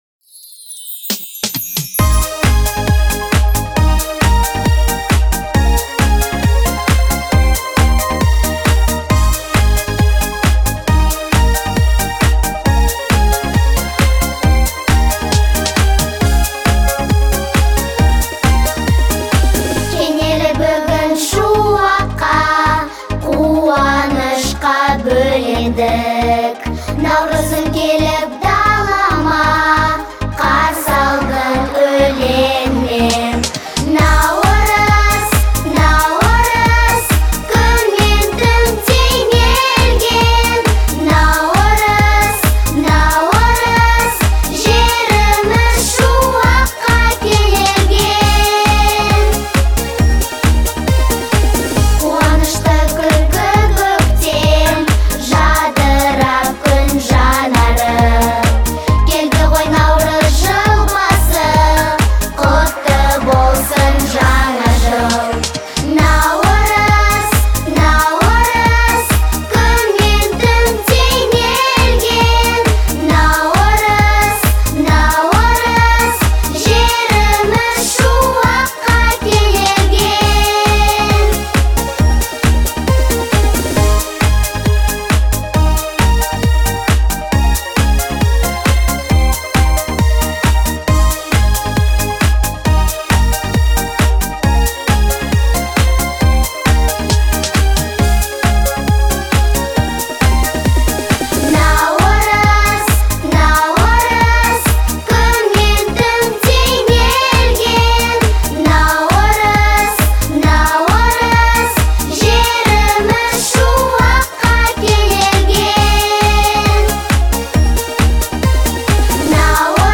это яркий пример казахского поп-музыки